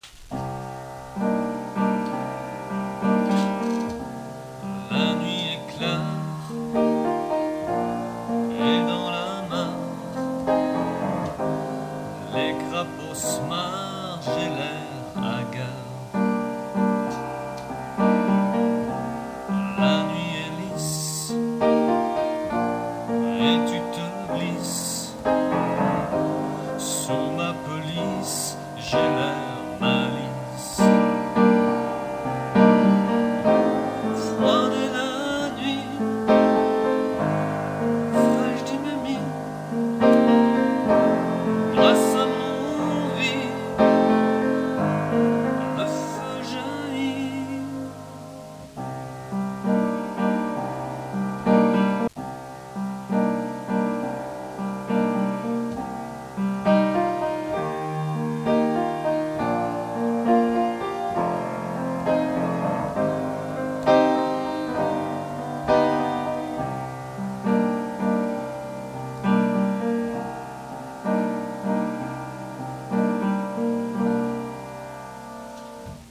Le texte de cette chanson suivi d’un extrait sonore chanté par le compositeur
pardonnez donc la qualité du son…un CD viendra sans doute un jour.
et maintenant l’enregistrement de travail (la nuit est clare 3)